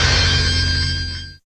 87 MACHINE-R.wav